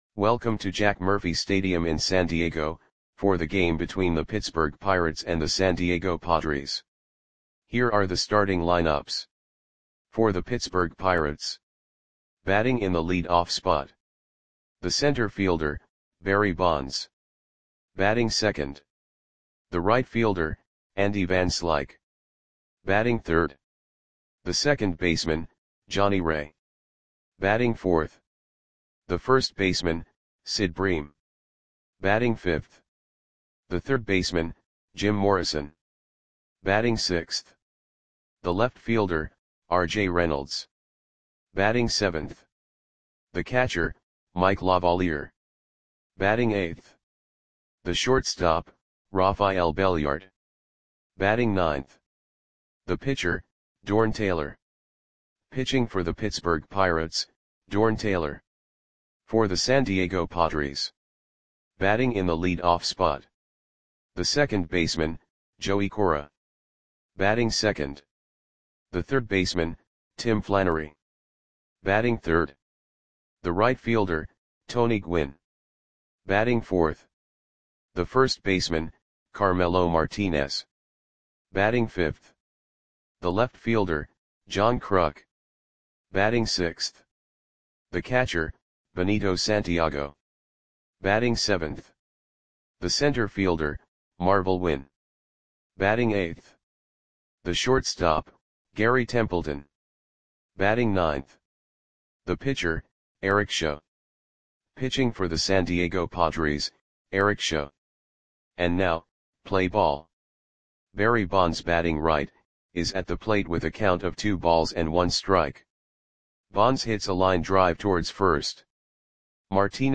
Audio Play-by-Play for San Diego Padres on May 5, 1987
Click the button below to listen to the audio play-by-play.